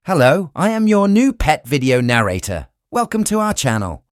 test_narrator_voice.mp3